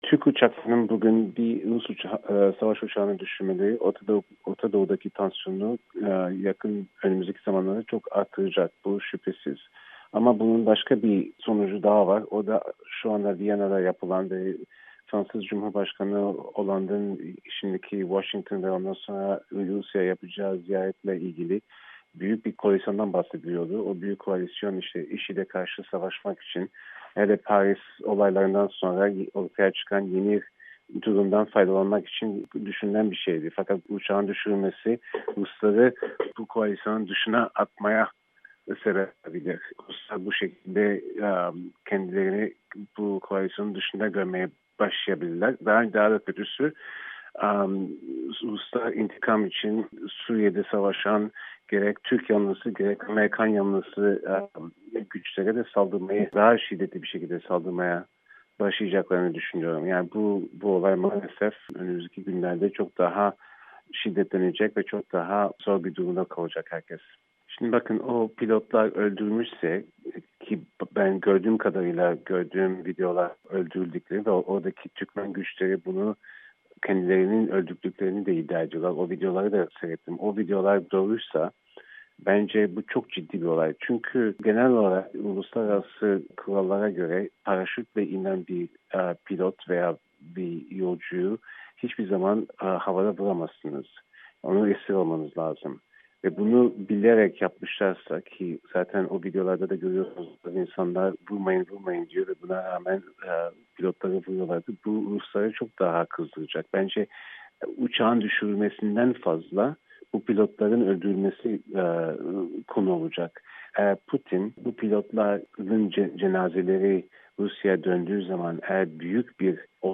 söyleşi